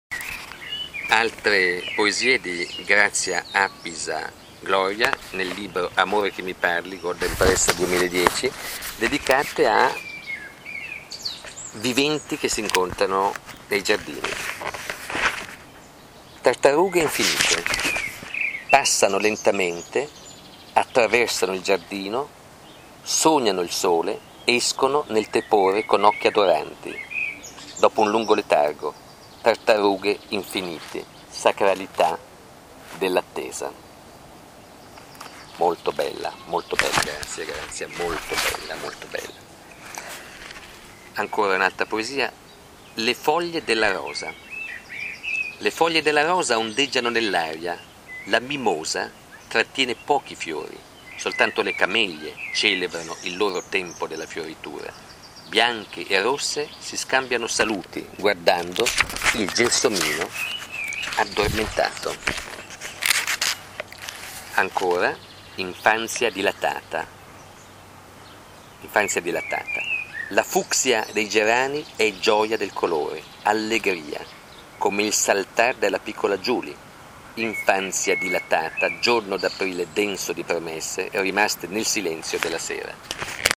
lettura ad alta voce